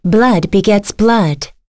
Lewsia_B-Vox_Skill1.wav